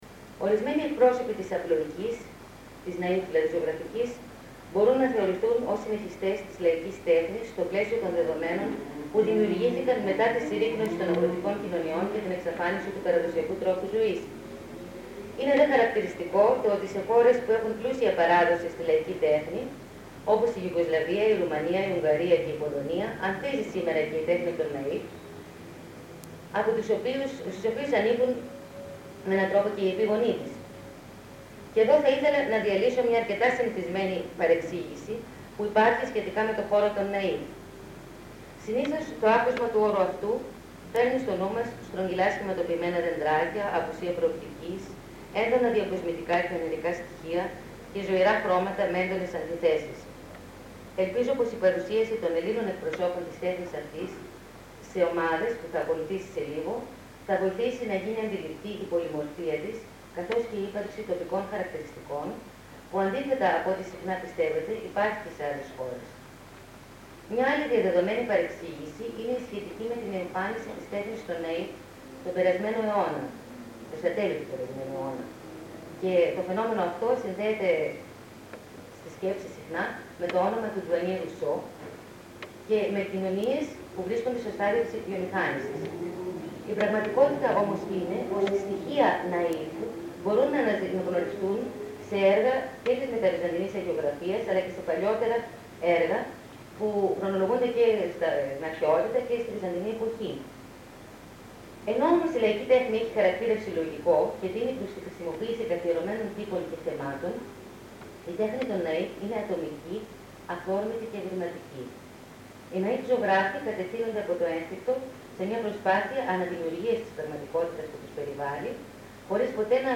Κύκλος ομιλιών στο ΛΕΜΜ-Θ.
Β' κύκλος ομιλιών. (EL)